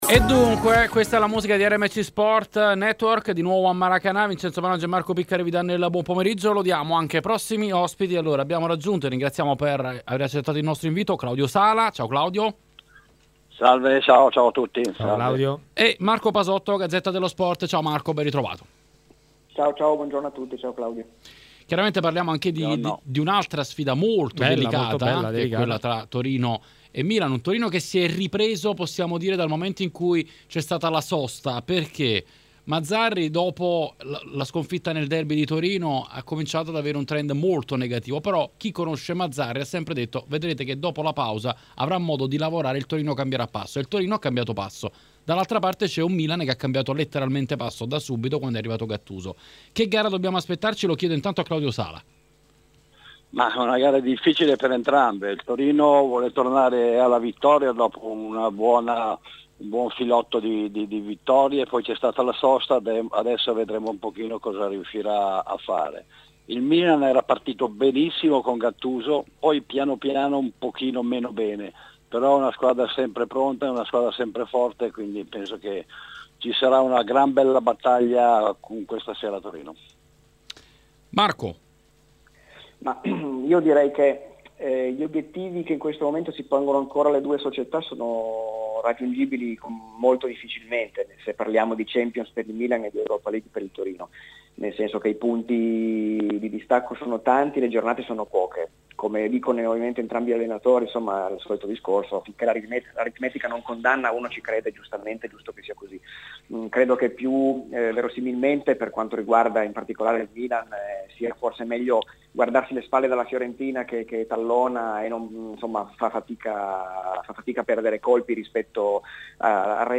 Per commentare Torino-Milan, gara in programma questa sera alle ore 20.45, su RMC Sport è intervenuto l'ex giocatore granata Claudio Sala: